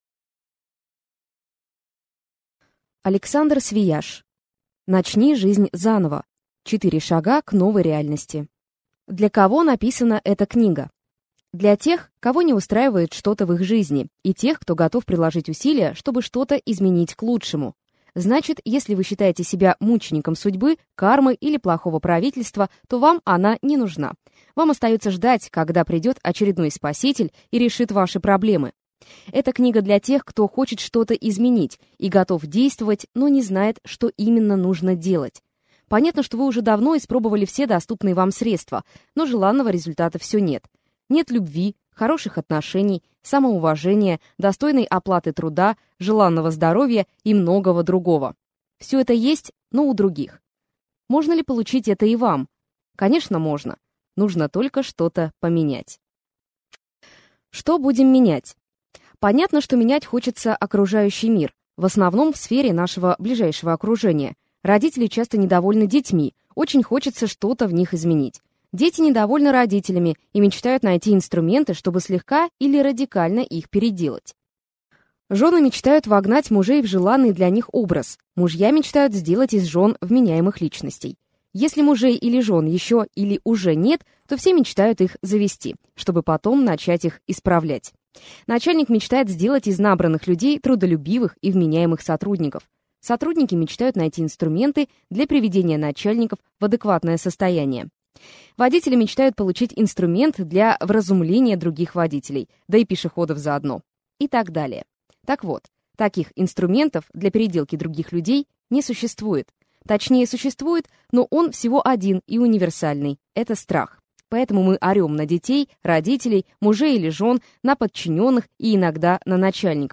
Аудиокнига Начни жизнь заново. 4 шага к новой реальности | Библиотека аудиокниг